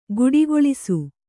♪ guḍigoḷisu